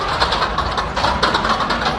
grasping_hands.ogg